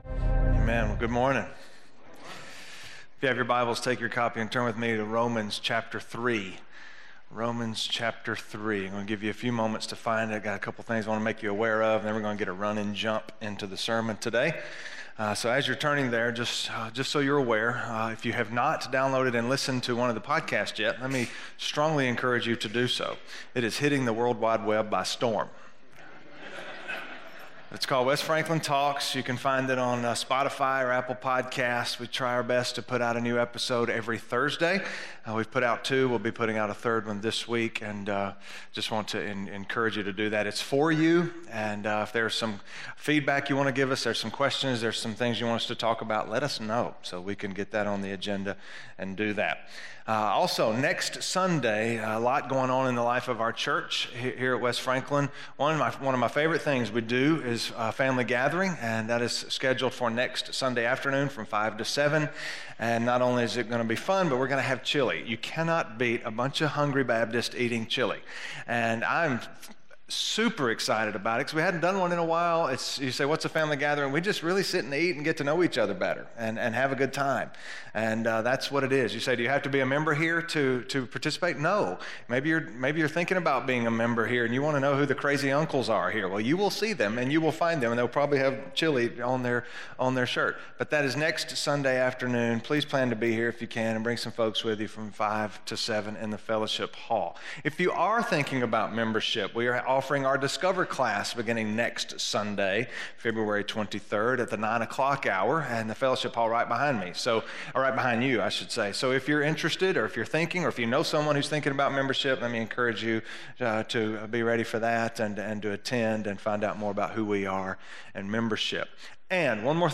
The Story of Redemption - Sermon - West Franklin